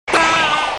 Hillary The Parrot Screaming Sfx Sound Effect Download: Instant Soundboard Button